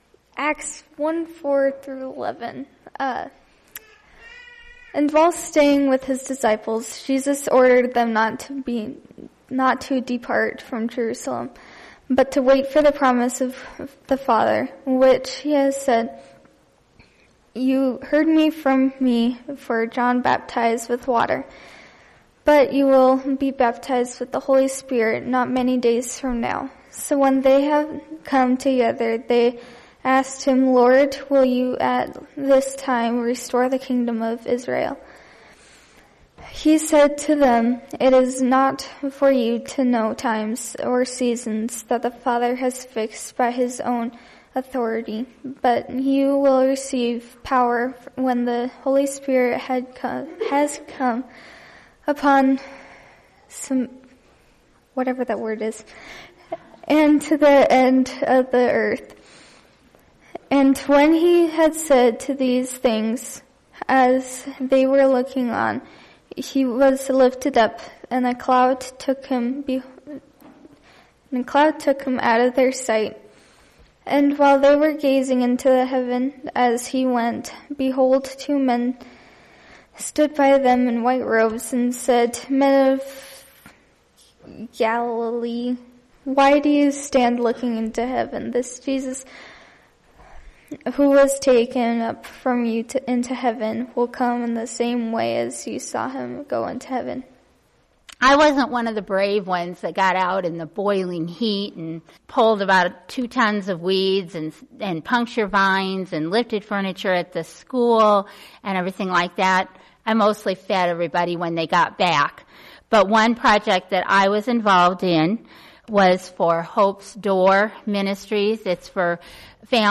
Mission Sunday: Member Reflections & Readings